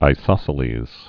(ī-sŏsə-lēz)